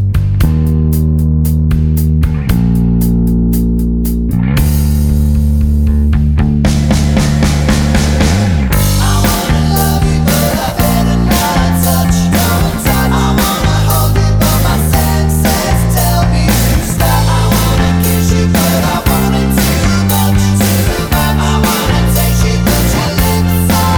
No Guitars with Backing Vocals Pop (1970s) 4:34 Buy £1.50